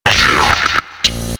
Android Infantry (Sound Voice)
The compilation contains 17 sounds, and here are samples of the android's best dialogue.
android_die2_900.wav